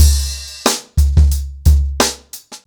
TheStakeHouse-90BPM.25.wav